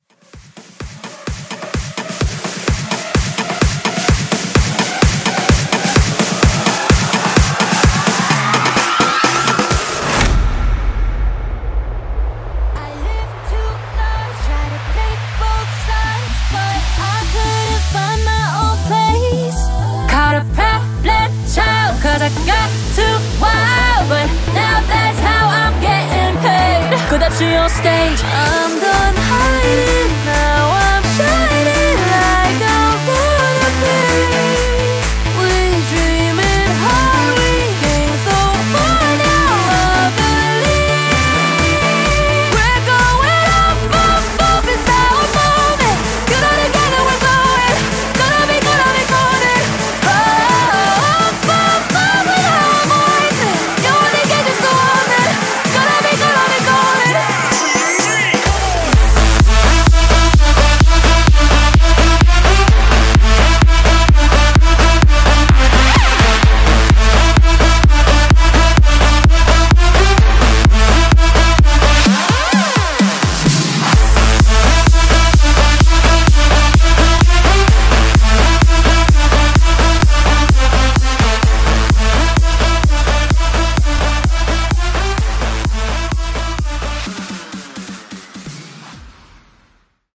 Epic Mashup)Date Added